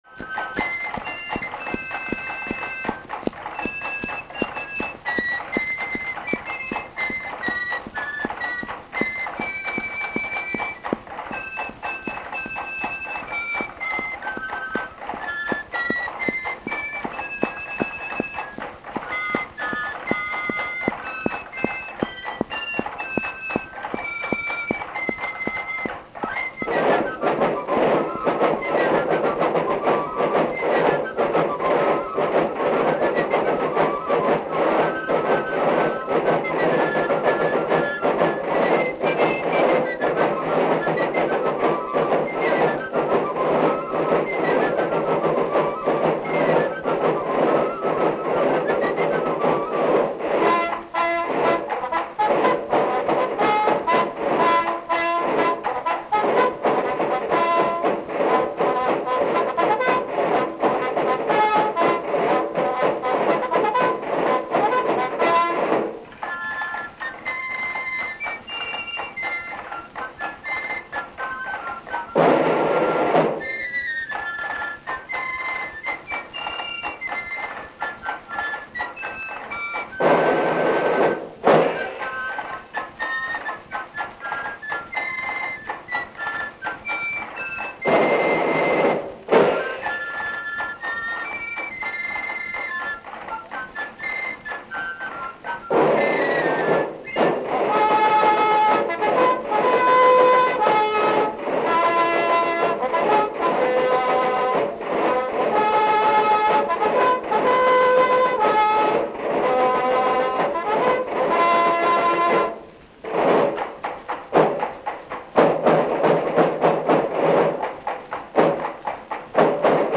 Cylinder of the day